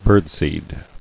[bird·seed]